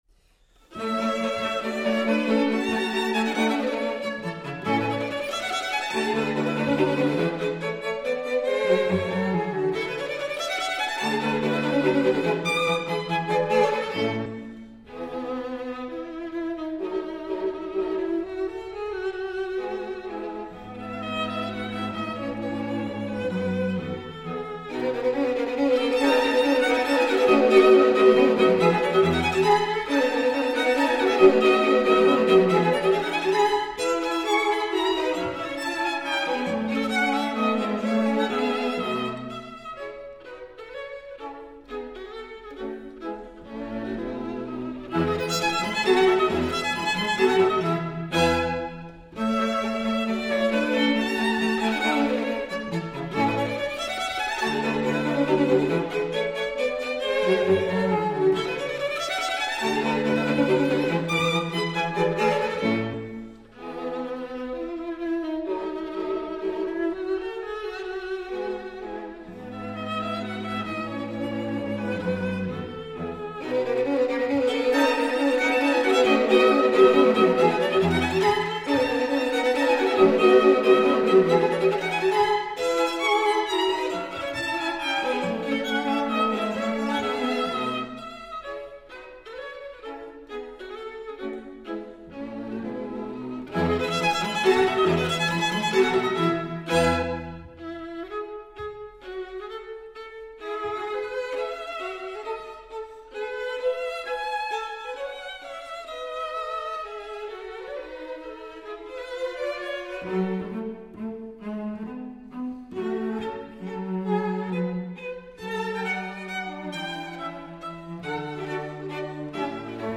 String Quartet in A major
Molto Allegro